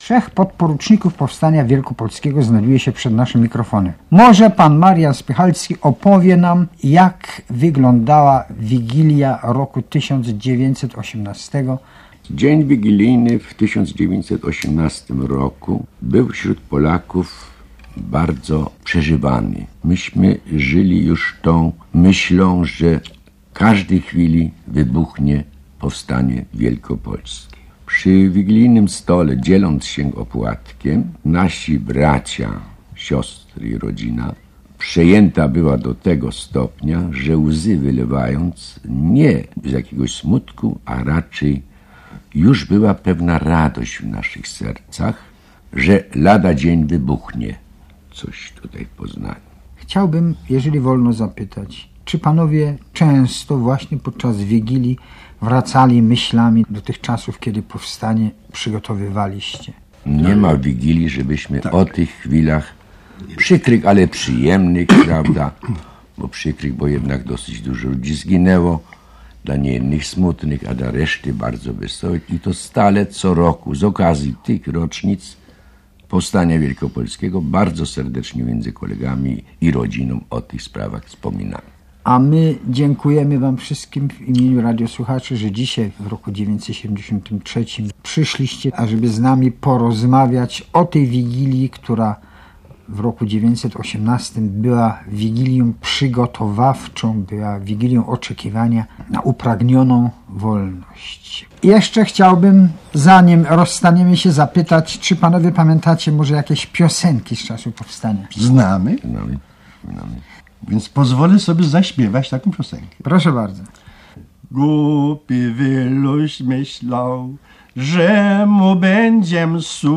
Właśnie mija 40 lat od audycji zarejestrowanej w studiu Polskiego Radia w Poznaniu, w której wzięli udział powstańcy wielkopolscy. Opowiadali o Wigilii 1918 roku. Śpiewali piosenki.